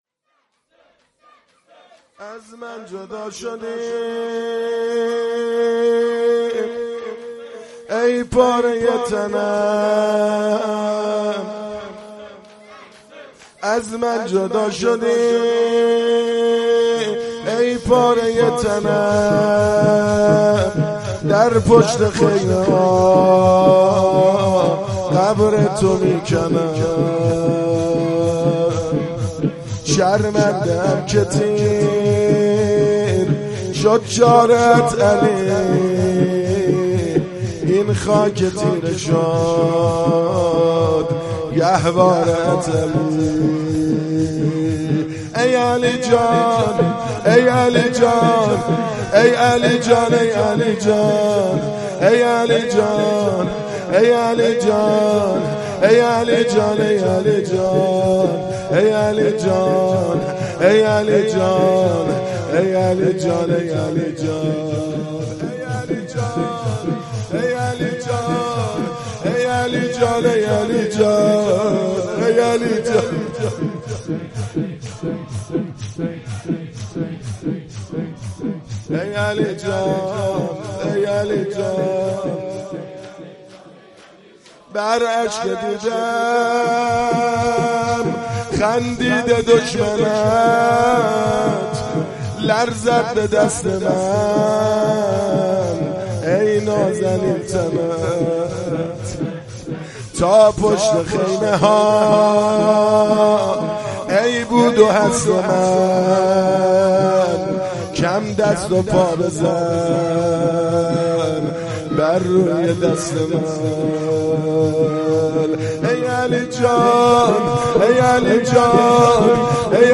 مداحی شب هفتم محرم 98 ( شور )
08-شور7.mp3